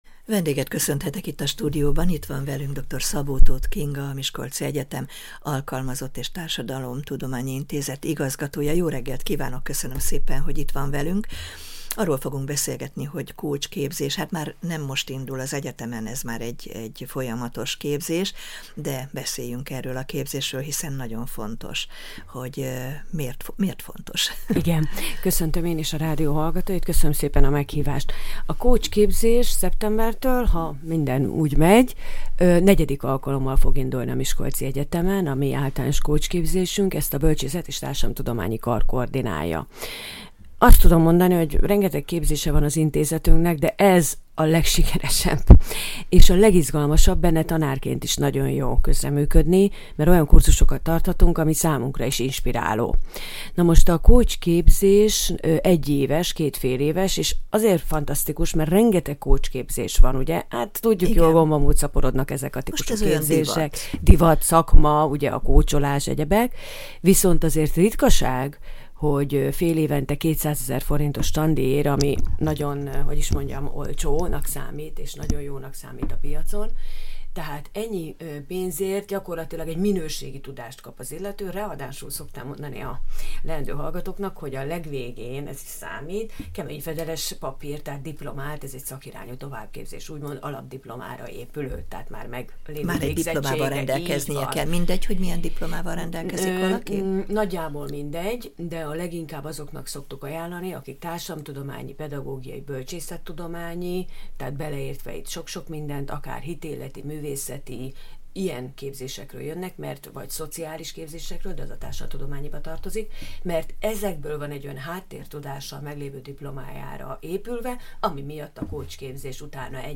egyetemi tanárral beszélgetett.